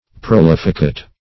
Search Result for " prolificate" : The Collaborative International Dictionary of English v.0.48: Prolificate \Pro*lif"ic*ate\, v. t. [See Prolific .] To make prolific; to fertilize; to impregnate.